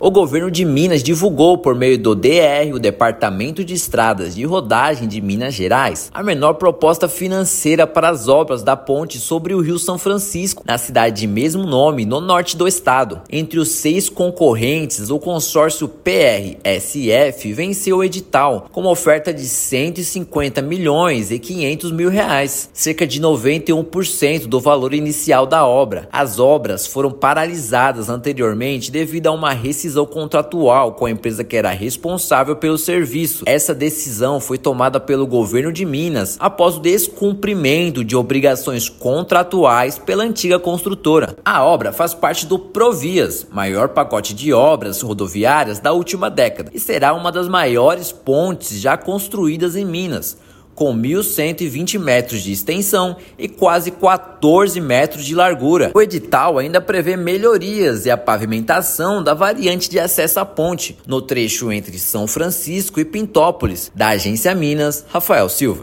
[RÁDIO] Governo do Estado seleciona consórcio para retomar as obras da ponte sobre o Rio São Francisco
União de três empresas apresenta menor preço para a construção da estrutura no Norte de Minas. Ouça matéria de rádio.